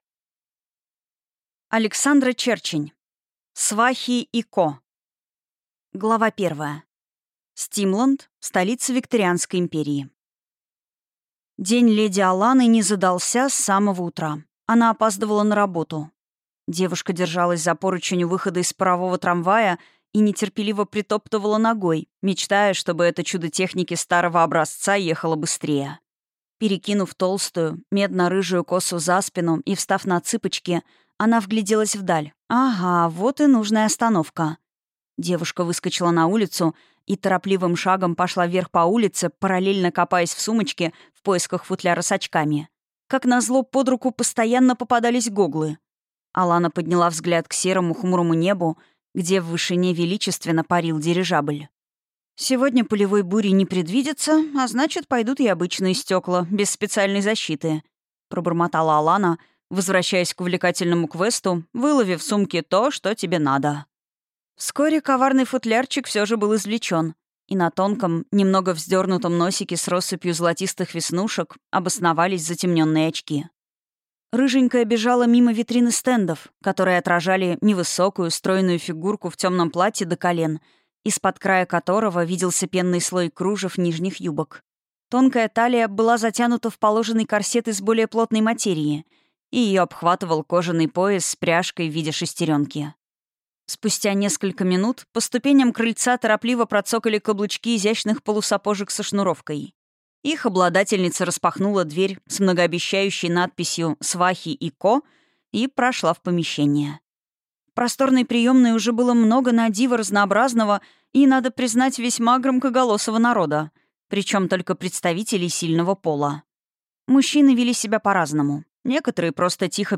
Мой драгоценный мужчина (слушать аудиокнигу бесплатно) - автор Ольга Шерстобитова